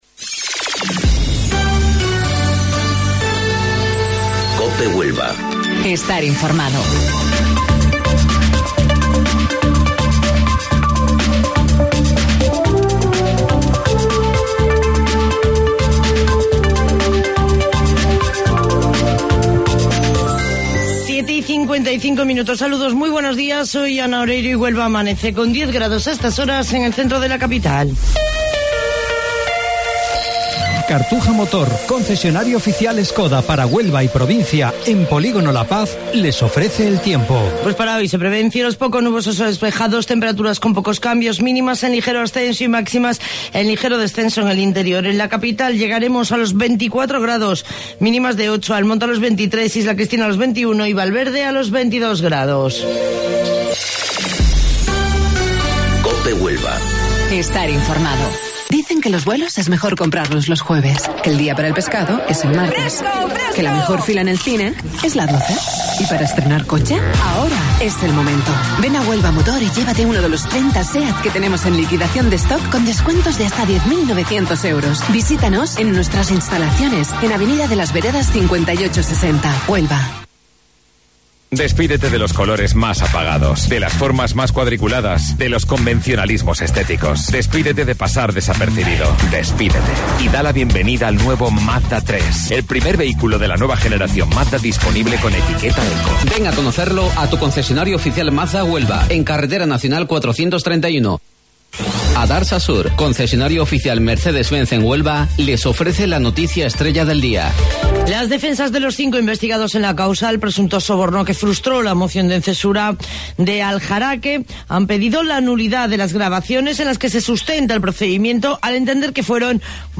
AUDIO: Informativo Local 07:55 del 19 de Marzo